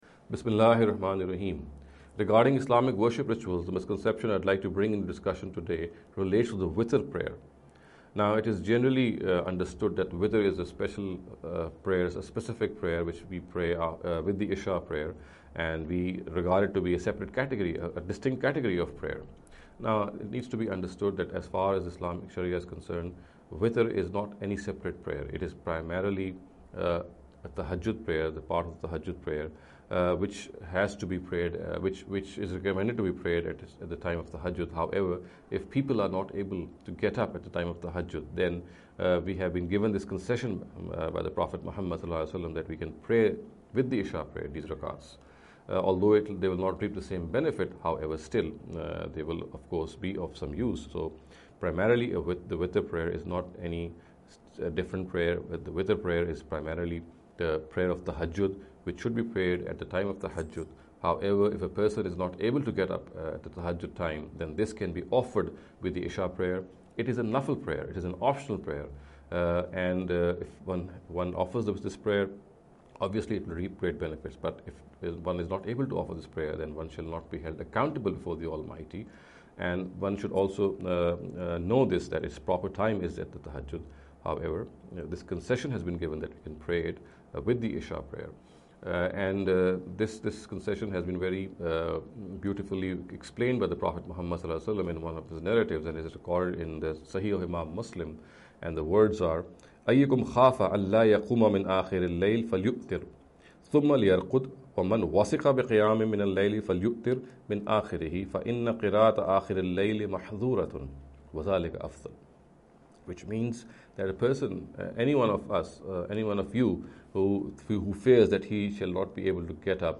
In the series of short talks “Islamic Worship Rituals: Some Misconceptions “